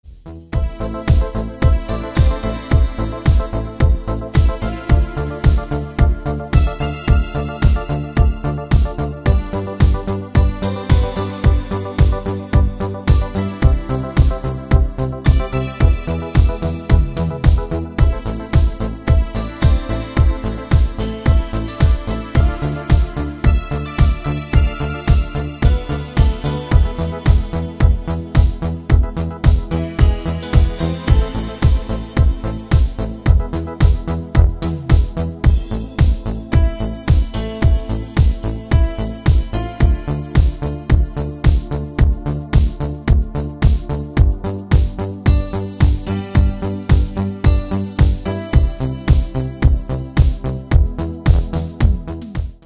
Рингтоны 80-х